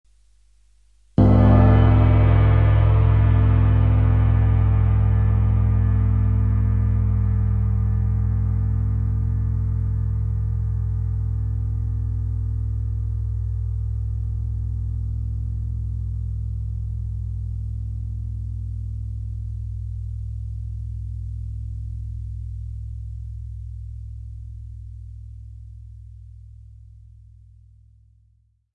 Download Cymbal sound effect for free.
Cymbal